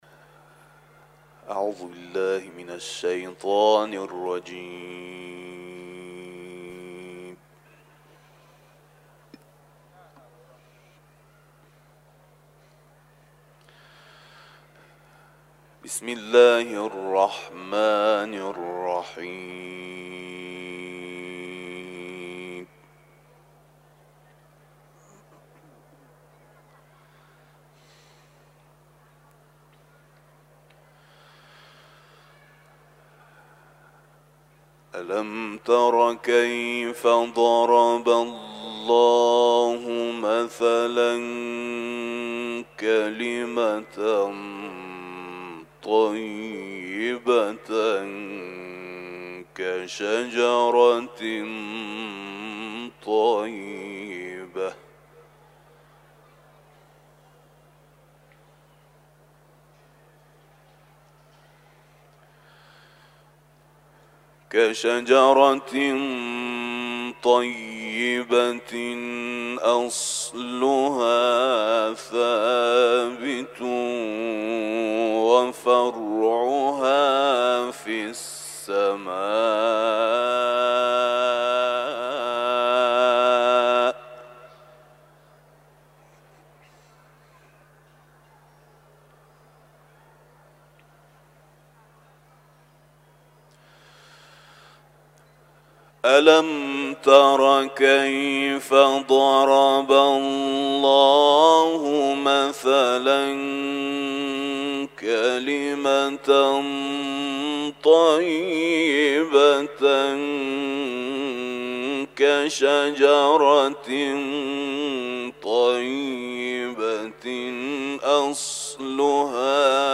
در جوار بارگاه منور حضرت رضا(ع) تلاوت کرده است.
تلاوت ، سوره ابراهیم